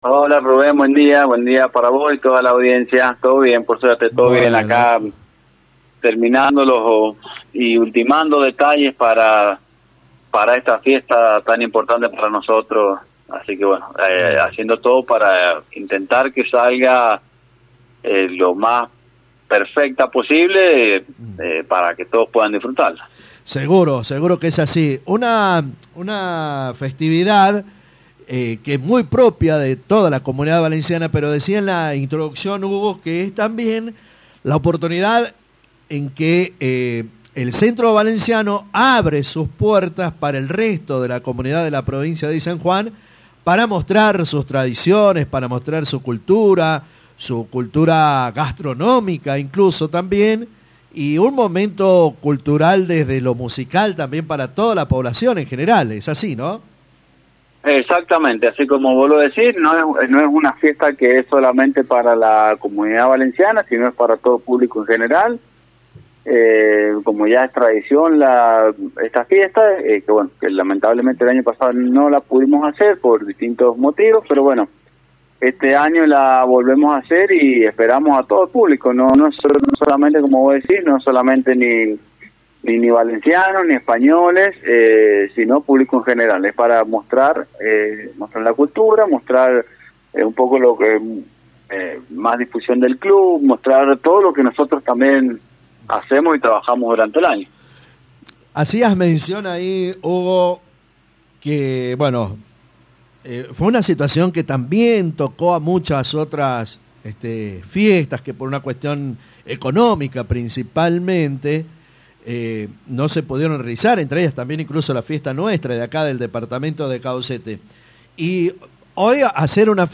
charló con Radio Genesis